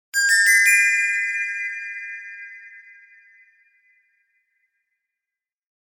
Light Sparkling Twinkle Sound Effect
Description: Light sparkling twinkle sound effect. It adds a gentle and magical sparkle to intros, transitions, and animations. It enhances fantasy scenes, notifications, and elegant moments with a soft and uplifting touch.
Light-sparkling-twinkle-sound-effect.mp3